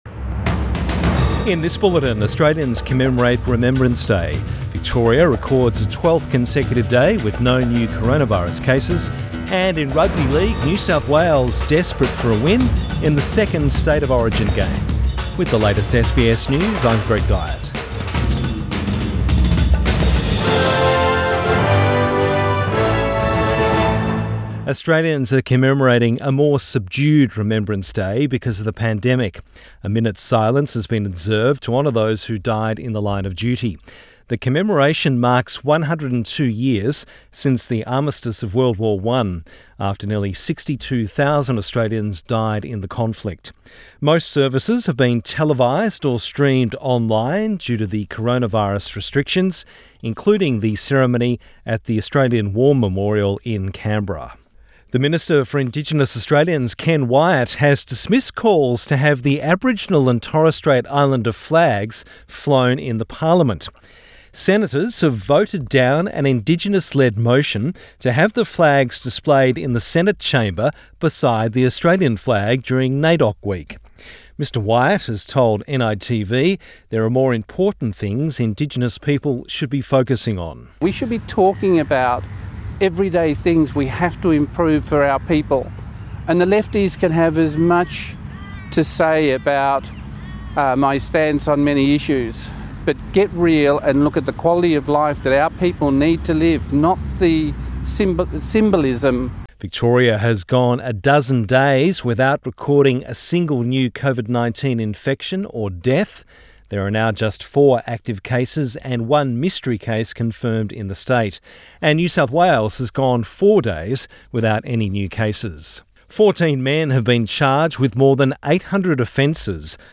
Midday bulletin 11 November 2020